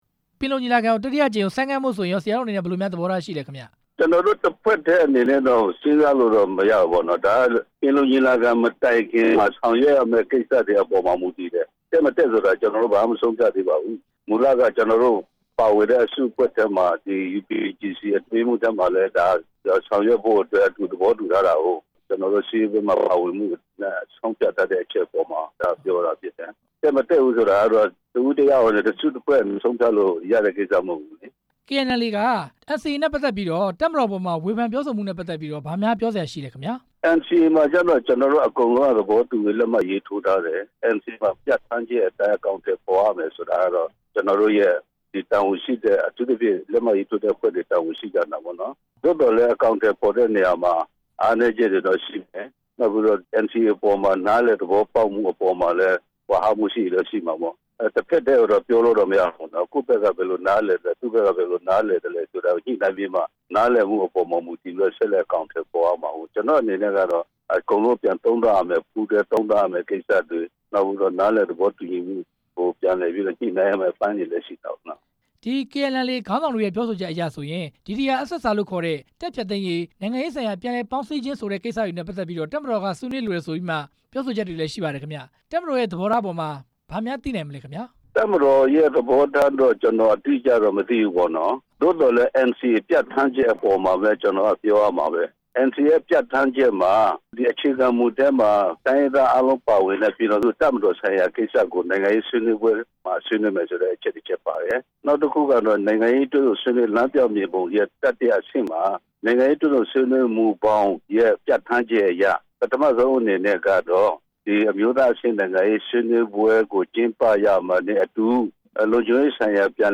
လက်နက်ဖြုတ်သိမ်းရေး KNU ဒုတိယဥက္ကဌနဲ့ မေးမြန်းချက်